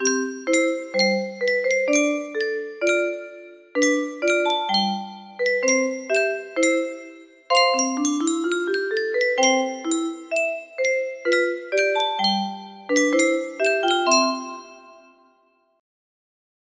Clone of Yunsheng Boite a Musique - Air Populaire 1484 music box melody